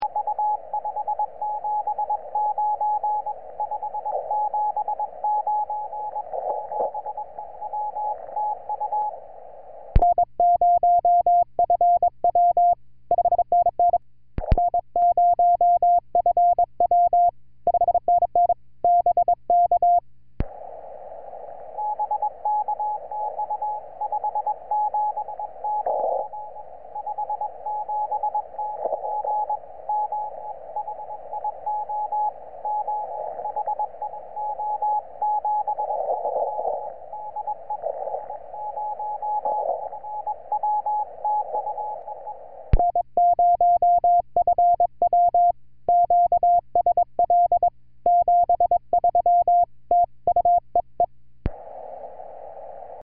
80MTR CW
80MTRS WAS VERY GOOD ON 30JUL2008 TO USA